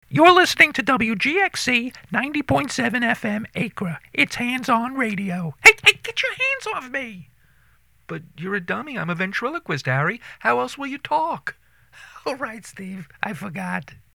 WGXC station identification.